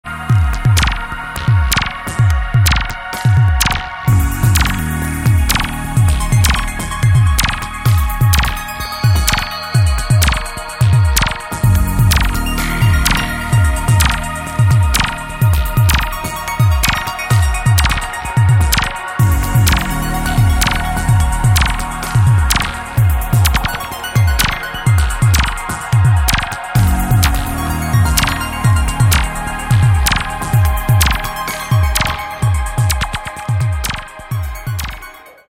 ELECTRO, BASS, BREAKS!